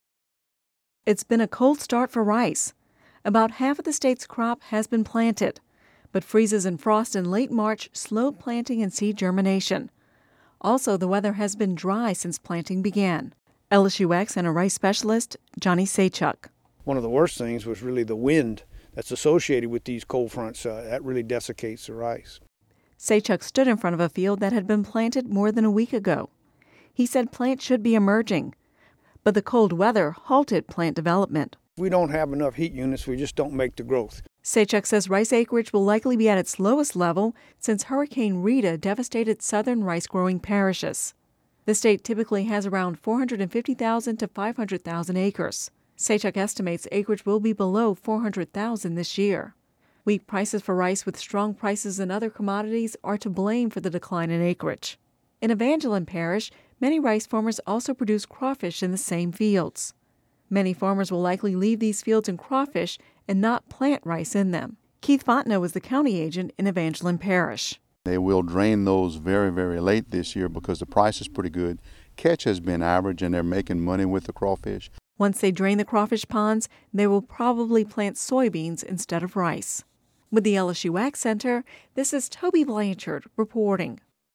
(Audio News 04/01/13) It’s been a cold start for rice. About half of the state’s crop has been planted, but freezes and frost in late March slowed planting and seed germination.